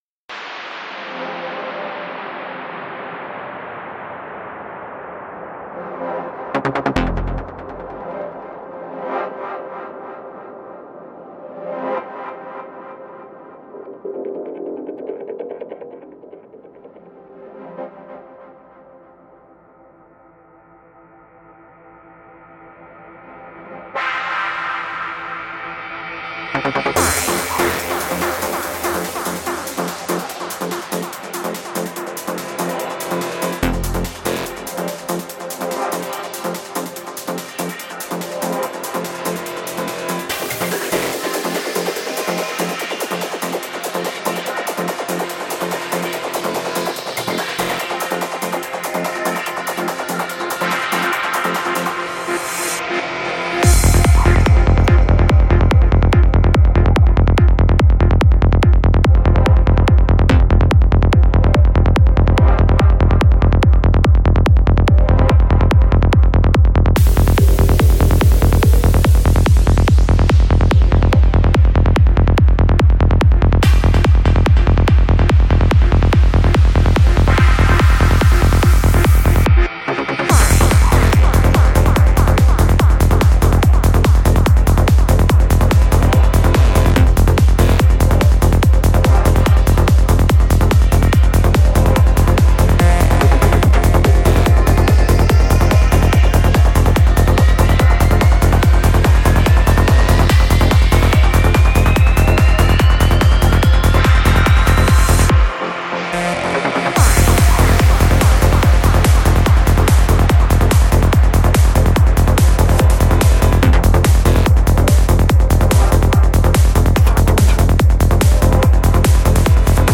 Жанр: Psy-Trance